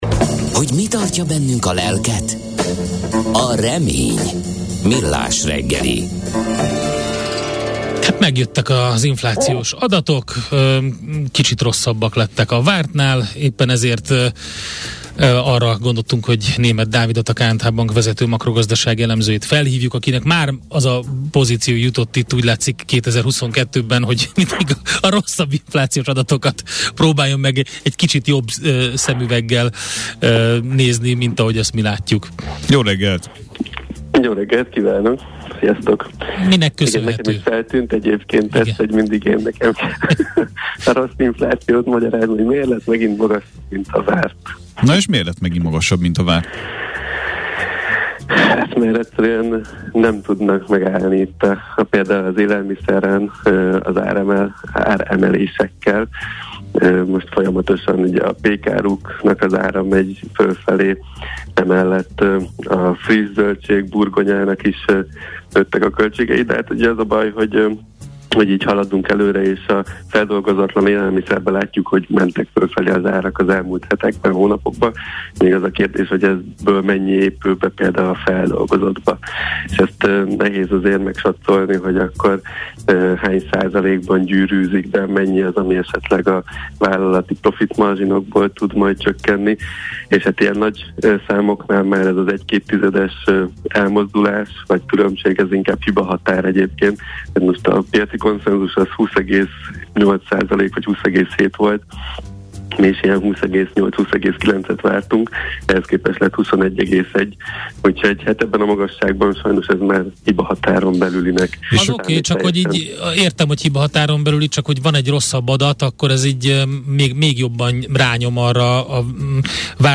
Műfaj: Blues.